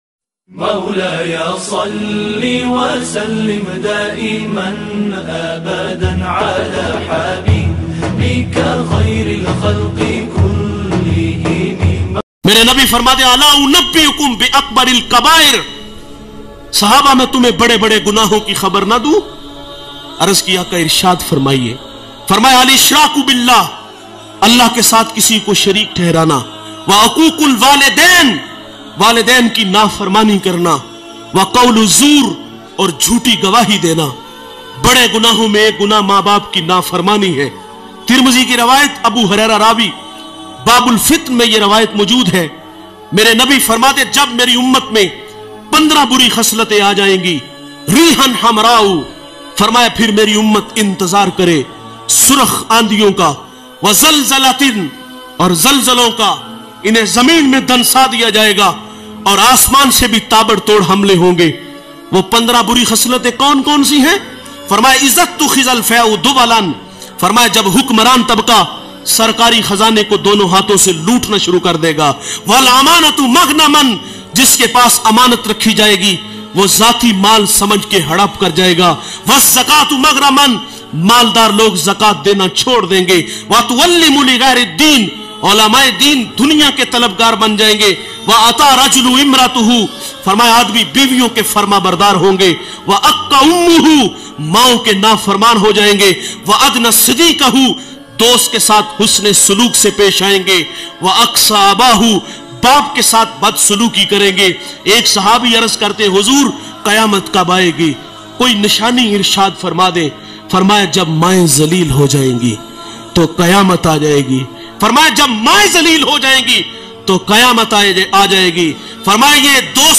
Qayamat Tab Aa Jayegi Jab bayan mp3